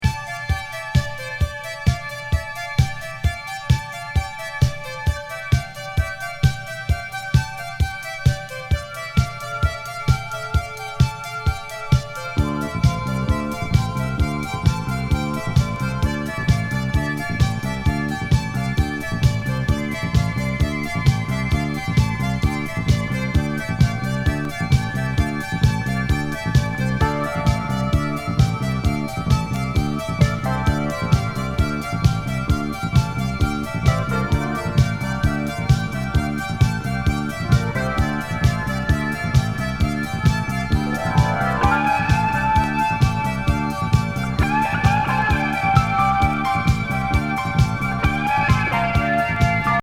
イントロからシンセ・コズミックなディスコ・ナンバー